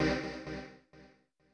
CLF Stab C1.wav